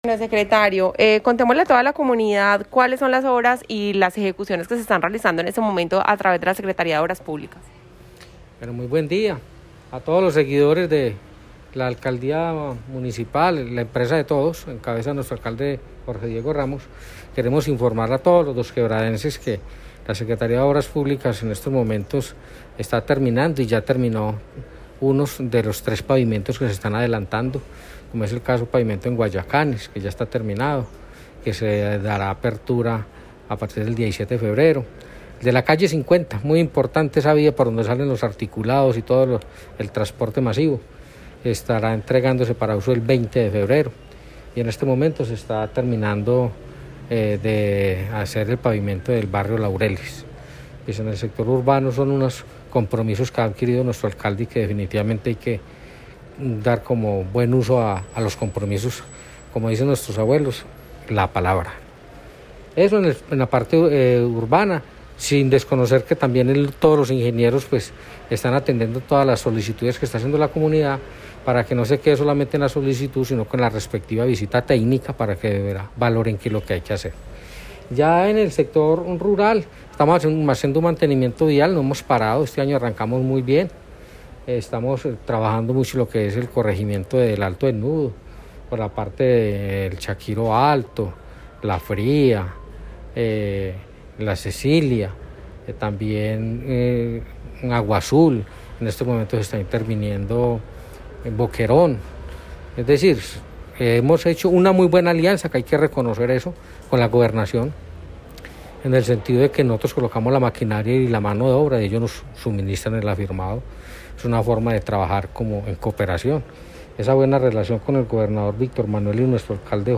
Comunicado-024-Audio-Jose-Jair-Serna-Secretario-de-Obras-Publicas.mp3